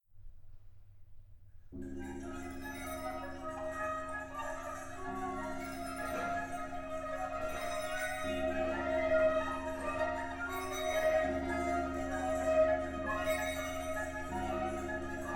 < The Crooked Horizon > for for Chamber Orchestra
UCSD⇔AUA Composers Concert
(Jun 2015, AUA chamber music hall)
Flute (Piccolo)
Vocal Soprano